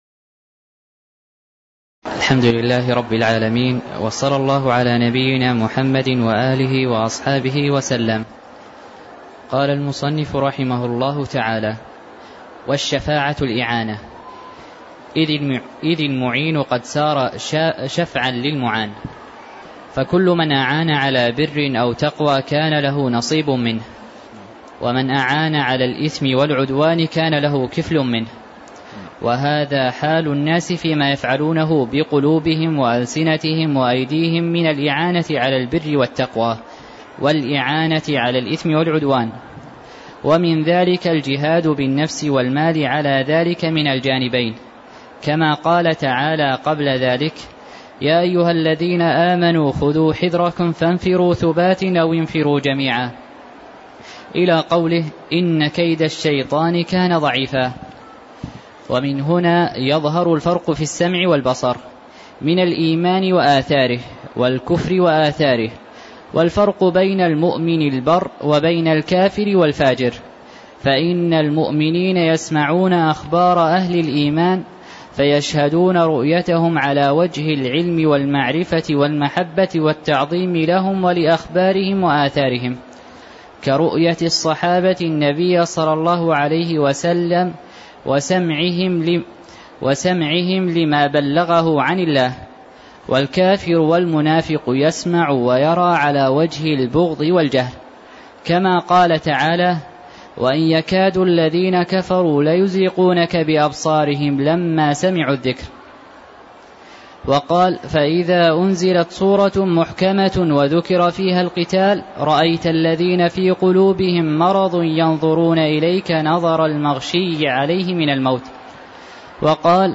تاريخ النشر ٢٥ جمادى الآخرة ١٤٣٦ هـ المكان: المسجد النبوي الشيخ: فضيلة الشيخ د. عبدالمحسن بن محمد القاسم فضيلة الشيخ د. عبدالمحسن بن محمد القاسم من قوله: والشفاعة الإعانة (041)